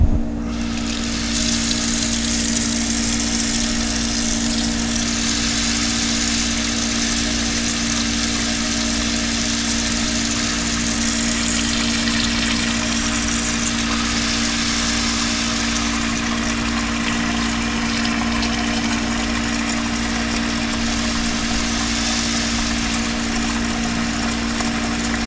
随机 "浴室水槽的水龙头流水，中短时+水龙头吱吱作响。
描述：从水龙头在浴室水槽med和短路+水龙头吱吱声turn.flac
Tag: 抽头 水槽 运行 打开 浴室